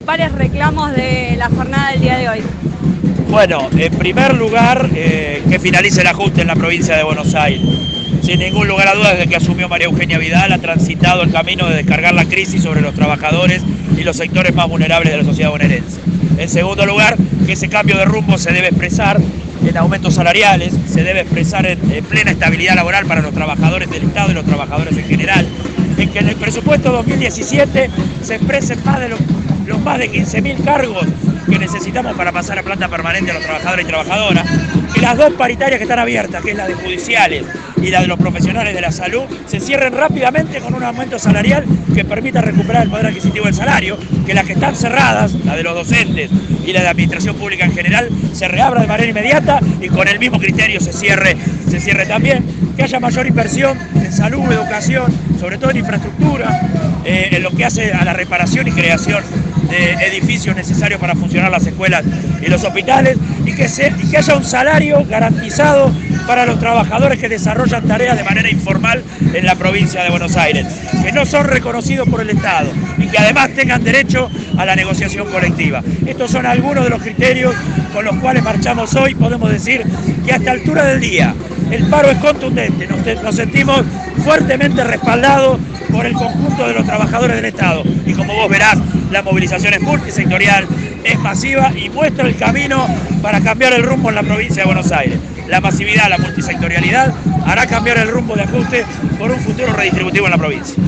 en diálogo con el móvil de Radio Estación Sur.